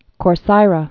(kôr-sīrə)